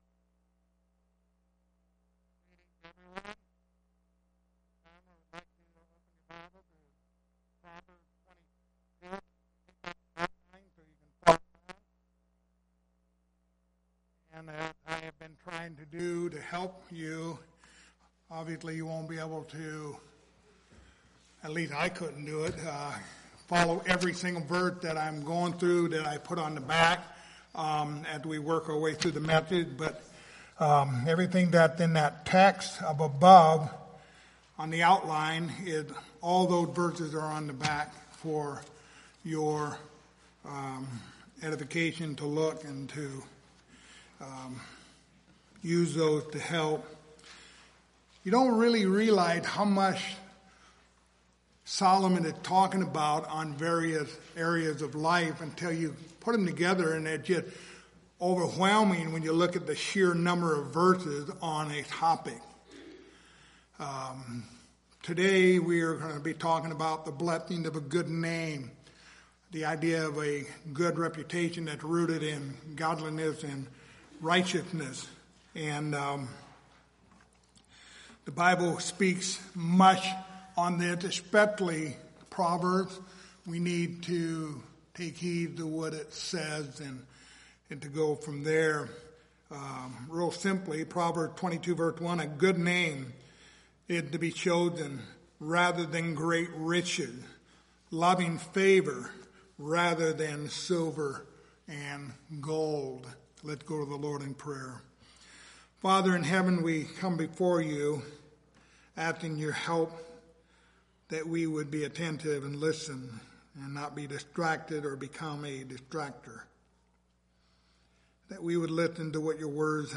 Passage: Proverbs 10:7 Service Type: Sunday Morning